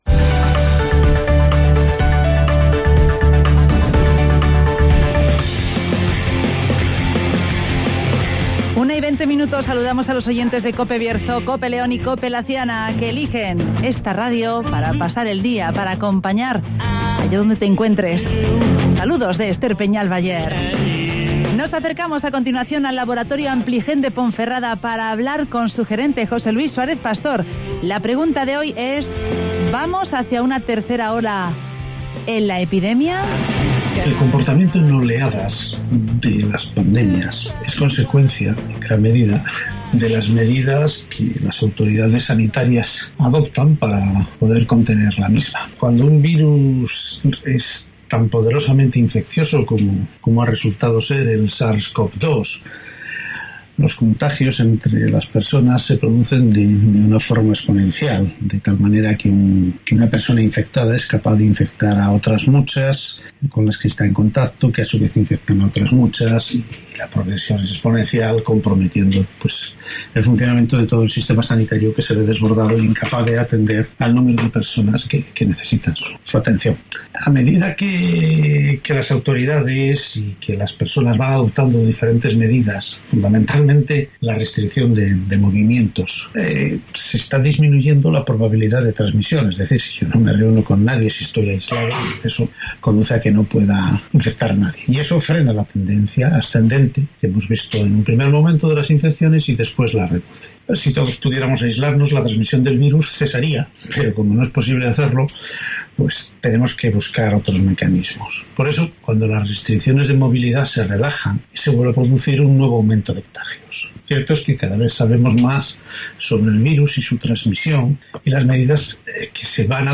y entrevista a Iván Alonso, concejal de Medio Rural de Ponferrada